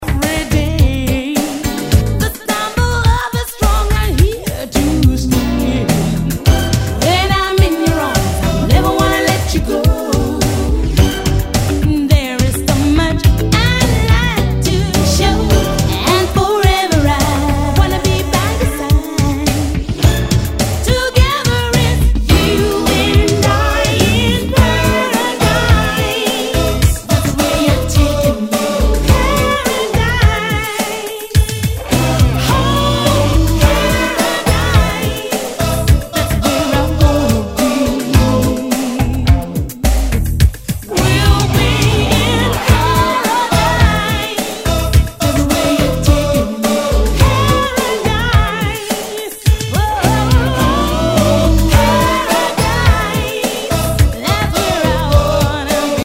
SOUL/FUNK/DISCO
ナイス！シンセ・ポップ・ソウル！